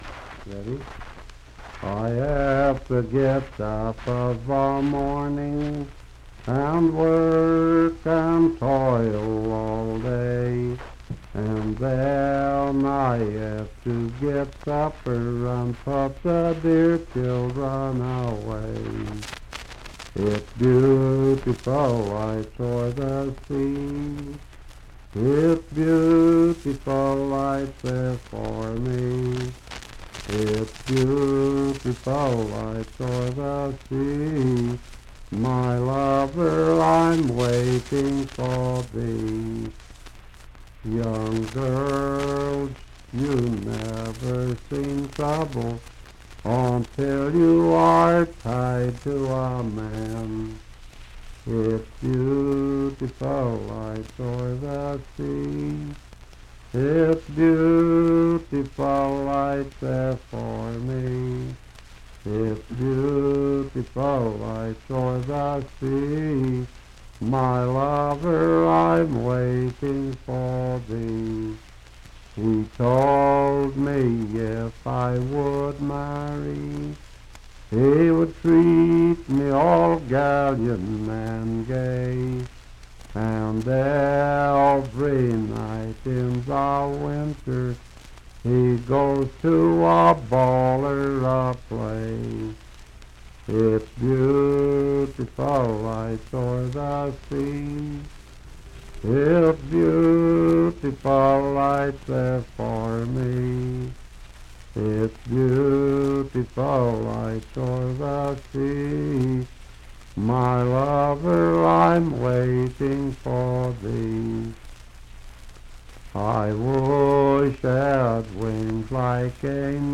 Unaccompanied vocal music
Voice (sung)
Marion County (W. Va.), Fairview (Marion County, W. Va.)